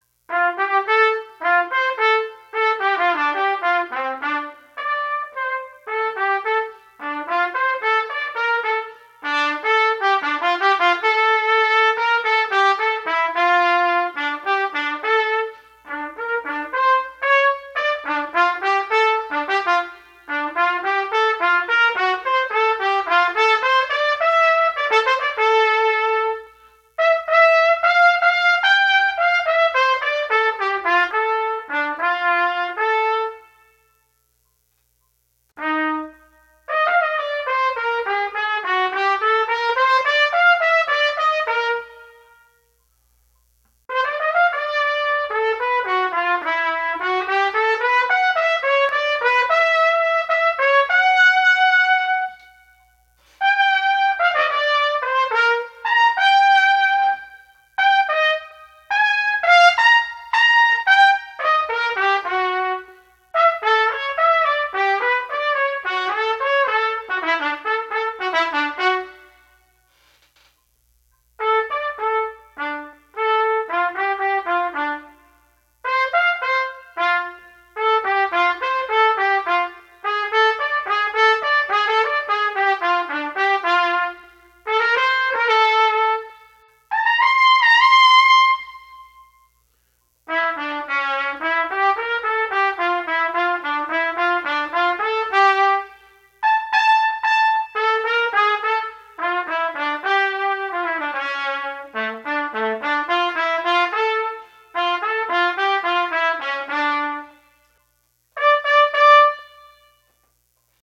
Trumpet
Bossa-Rock Etude
Complete Performance
bossa-rock-etude-set-1-v2.m4a